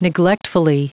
Prononciation du mot neglectfully en anglais (fichier audio)
Prononciation du mot : neglectfully